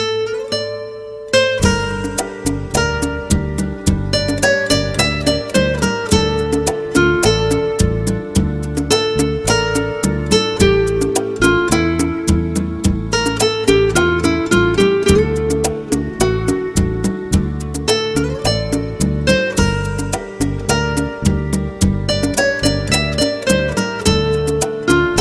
Kategorien Klassische